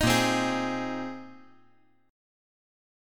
Listen to A#7sus2sus4 strummed